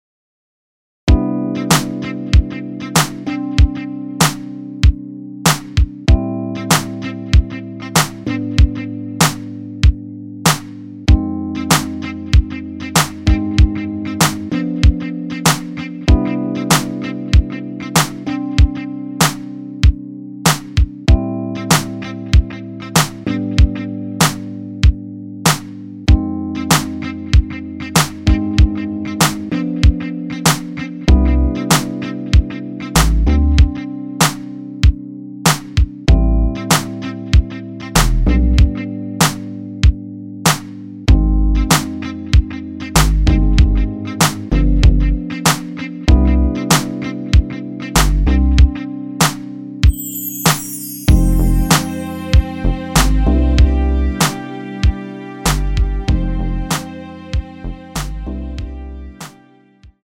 엔딩이 페이드 아웃이라 라이브 하시기 좋게 엔딩을 만들어 놓았습니다.(멜로디 MR 미리듣기 참조)
원키에서(-2)내린 MR입니다.
앞부분30초, 뒷부분30초씩 편집해서 올려 드리고 있습니다.
중간에 음이 끈어지고 다시 나오는 이유는